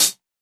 Closed Hats
Dilla Hat 50.wav